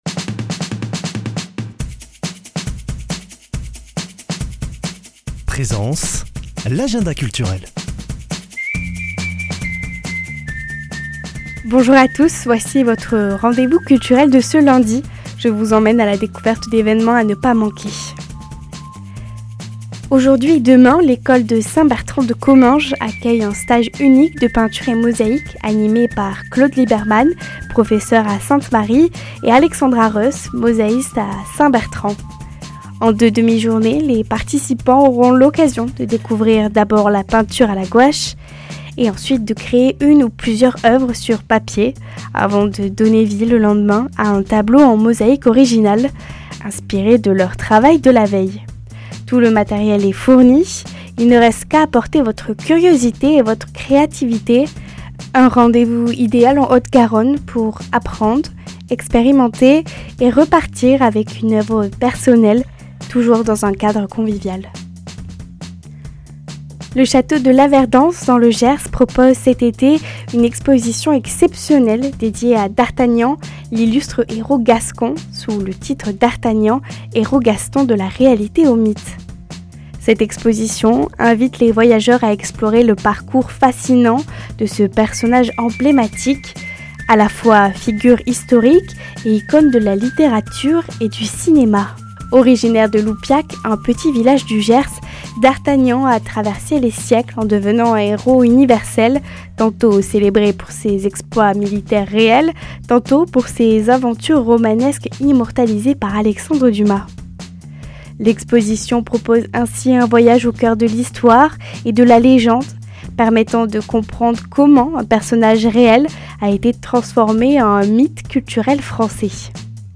Une émission présentée par
Présentatrice